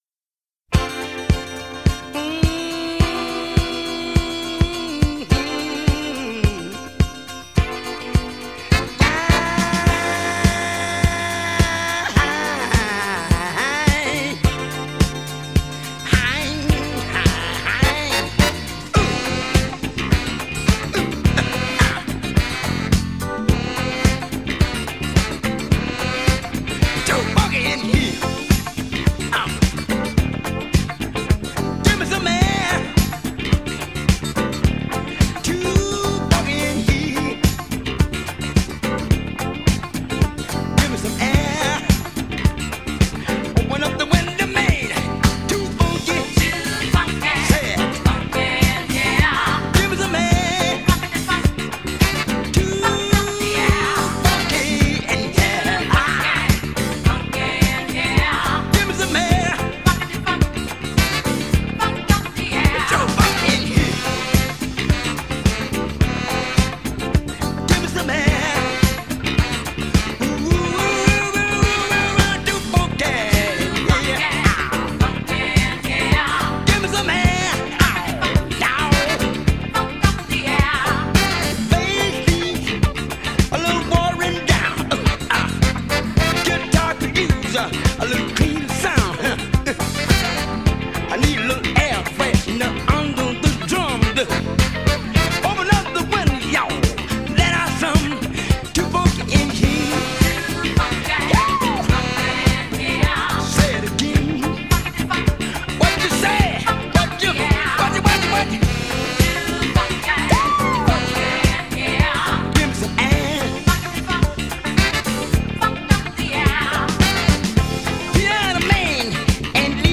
Adjective Battleship: Z’that a flute or people?